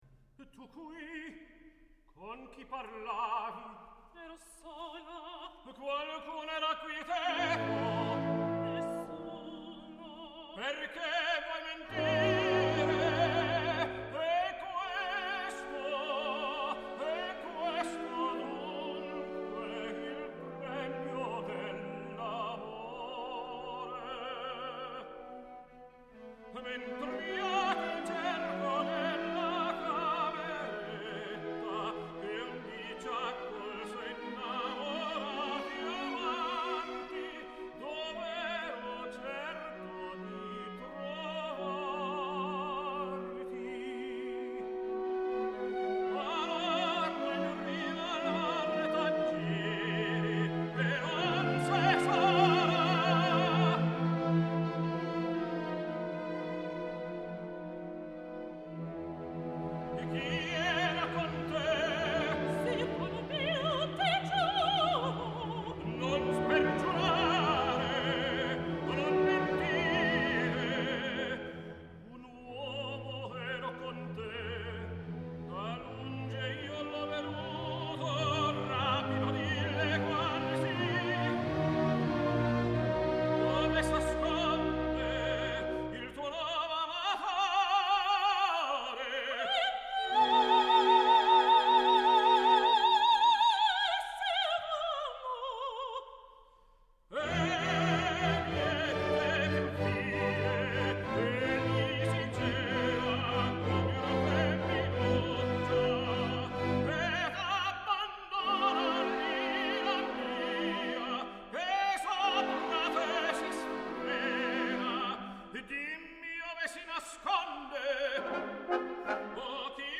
tenor
soprano
baritone
mezzo-soprano